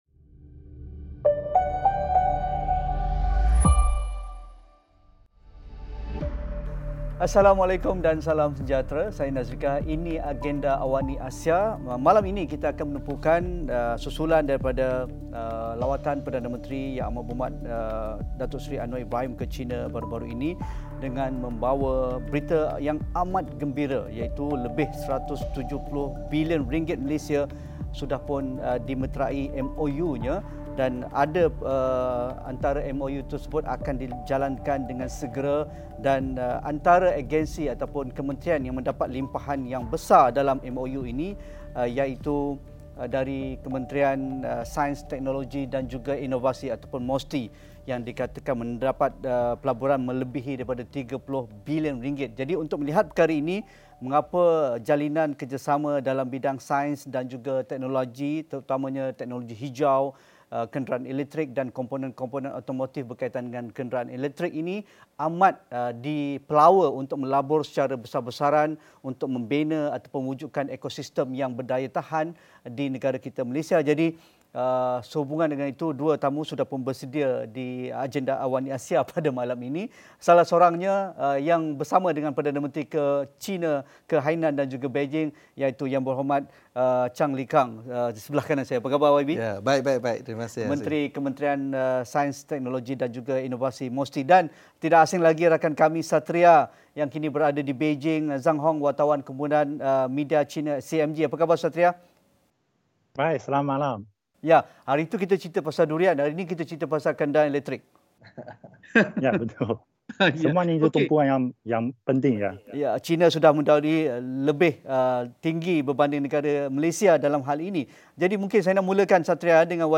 Sejauhmanakah pelaburan berimpak tinggi memacu pembangunan STI negara? Diskusi Sabtu 9 malam.